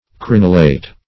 Crenulate \Cren"u*late\ (kr?n"?-l?t), Crenulated \Cren"u*la`ted\